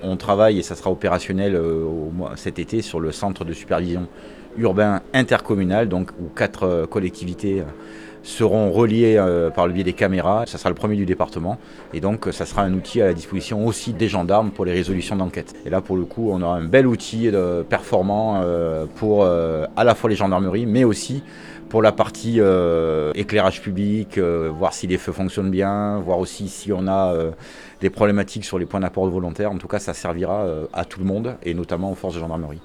Il permettra une meilleure surveillance, selon Jean-Philippe Mas, le maire de Cluses.
ITG Jean Philippe Mas 2 - vélo gendarmerie Scionzier_WM.wav